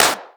pdh_snare_one_shot_synthetic_crunch.wav